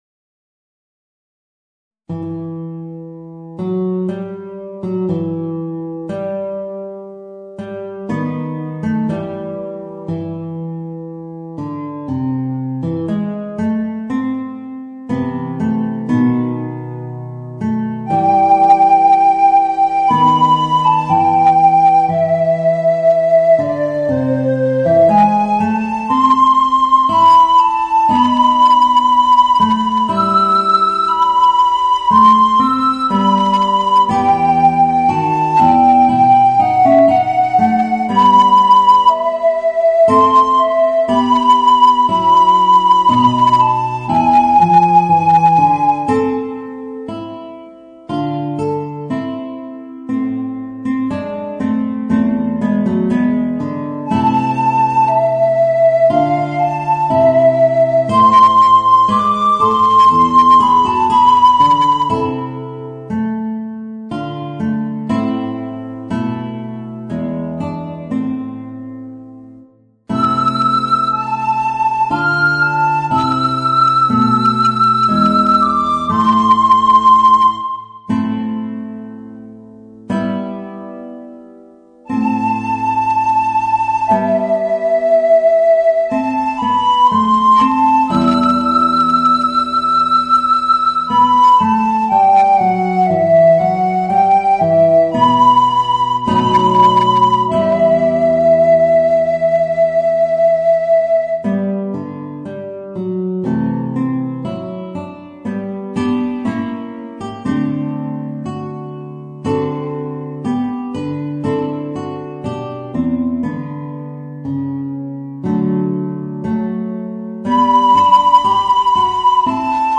Voicing: Guitar and Soprano Recorder